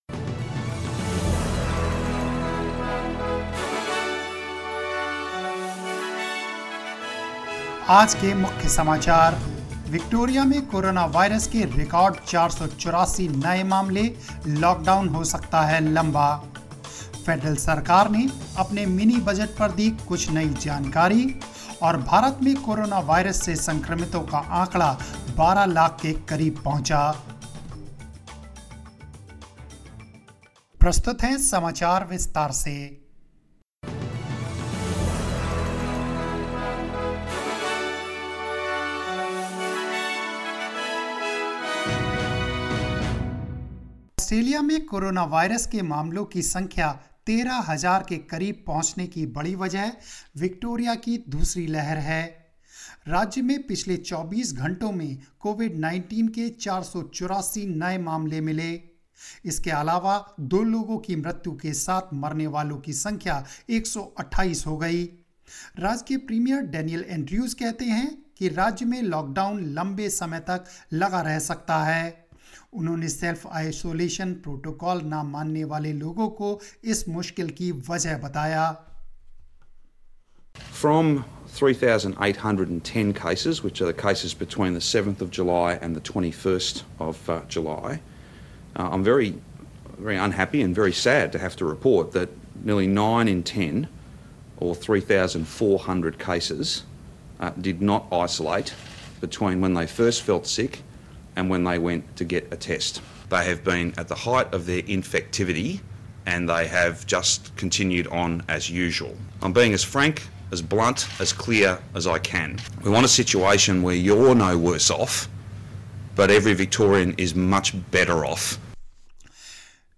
News in Hindi 22 July 2020